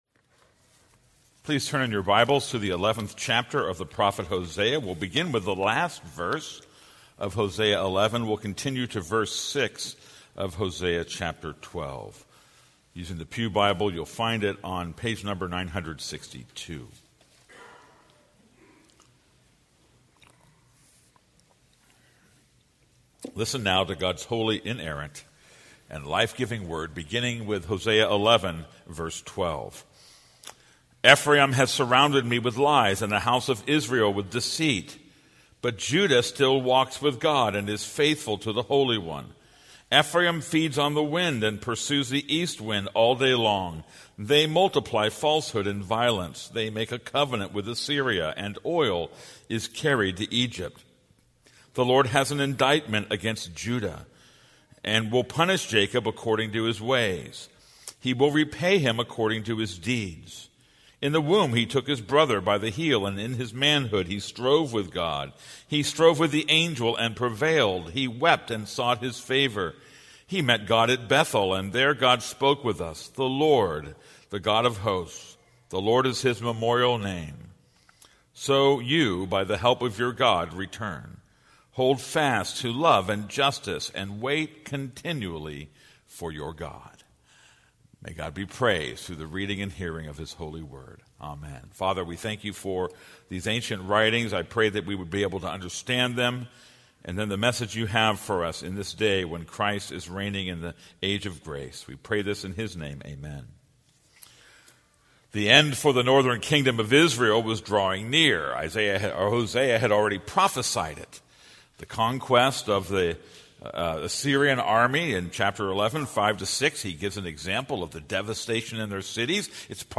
This is a sermon on Hosea 11:12-12:9.